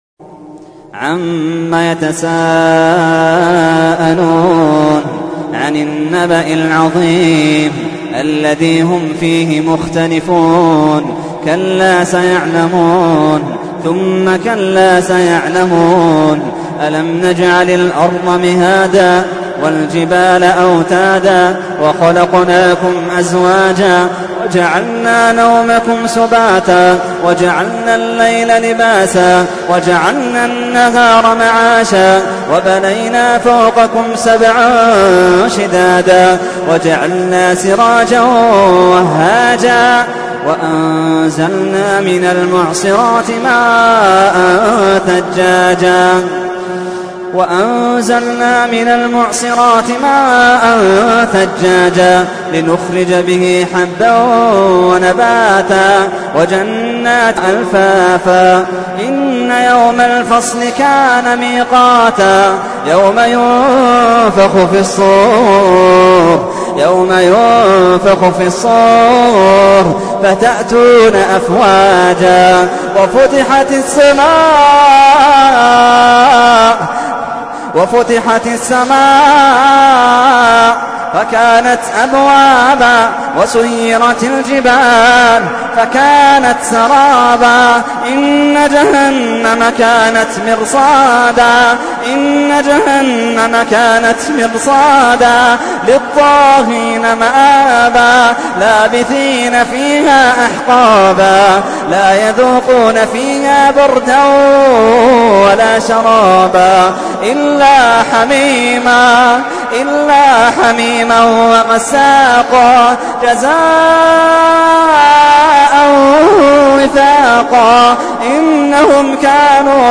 تحميل : 78. سورة النبأ / القارئ محمد اللحيدان / القرآن الكريم / موقع يا حسين